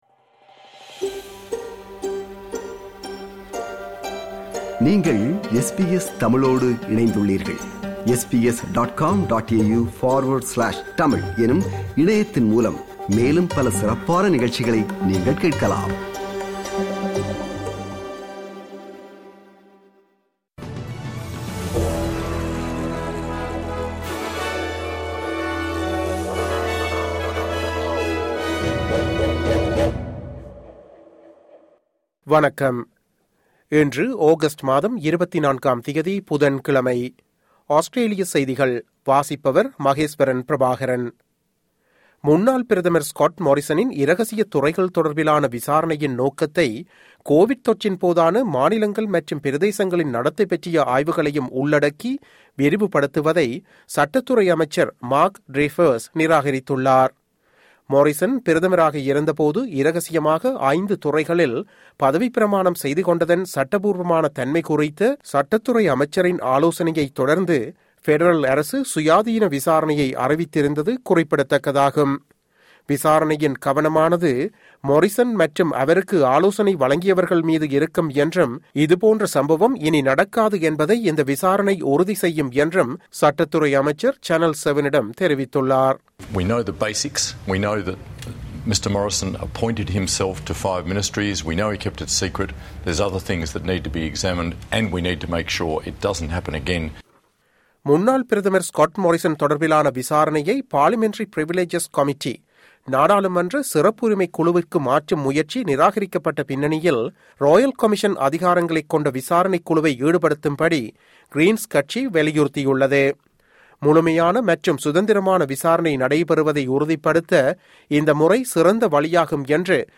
Australian news bulletin for Wednesday 24 August 2022.